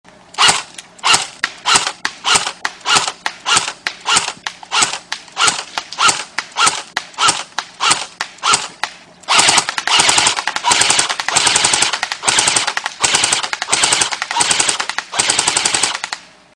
Sounds of Strikeball Drive (weapons) download and listen online for free